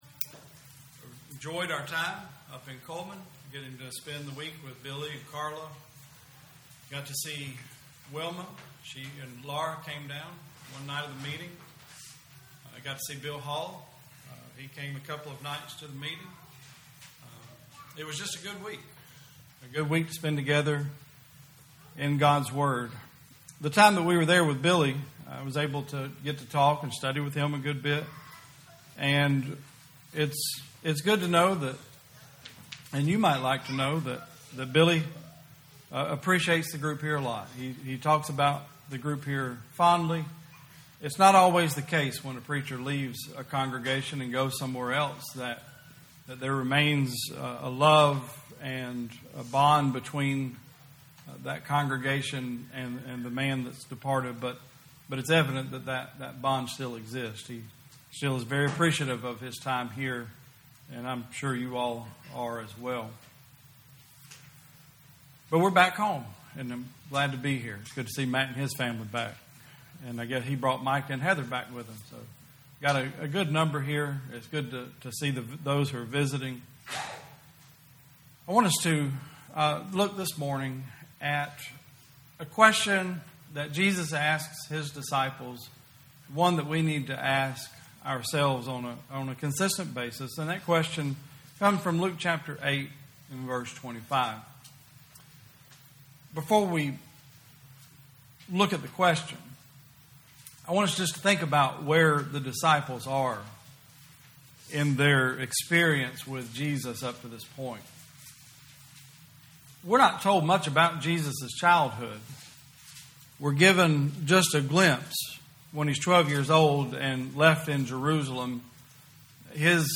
Luke 8:25 Service Type: Sunday Service Bible Text